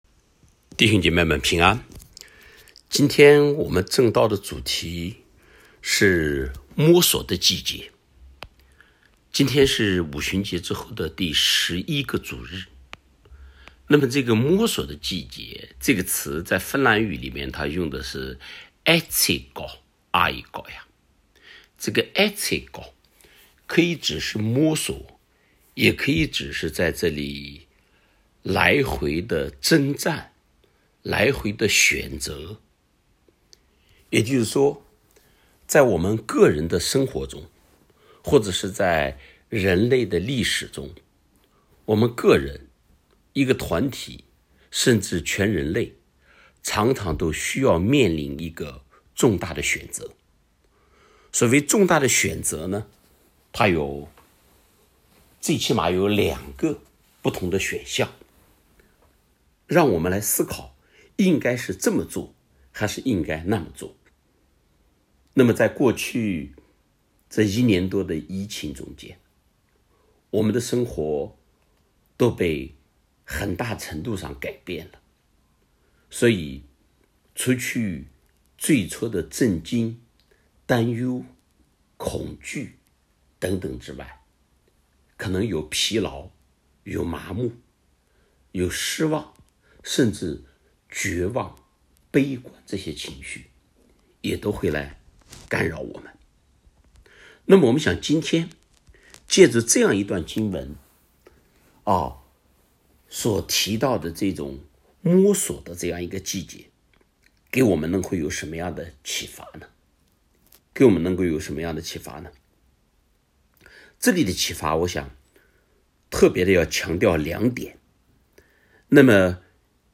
2021年8月8日线上主日礼拜 摸索的季节
由于特殊时期特殊安排，本教会的本日礼拜在线上进行，请大家按照以下程序，在家敬拜赞美上帝。